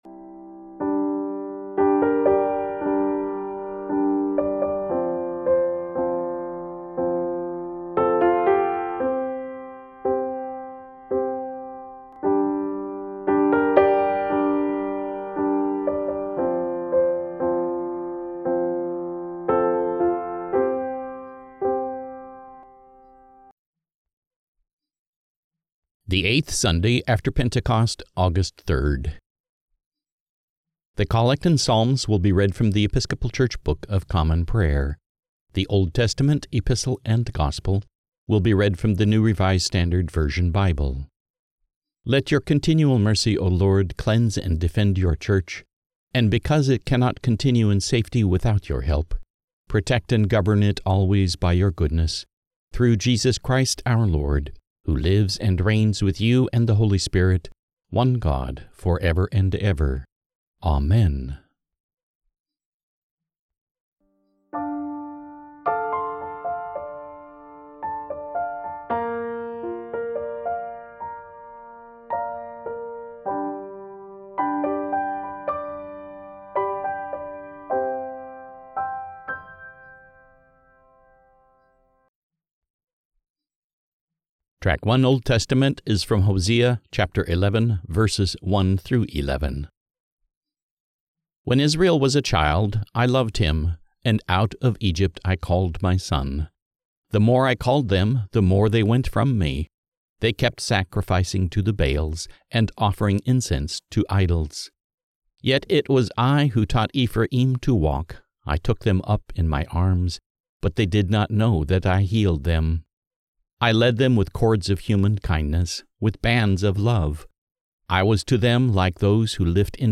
The Collect and Psalms will be read from The Episcopal Church Book of Common Prayer
The Old Testament, Epistle and Gospel will be read from the Revised Standard Version Bible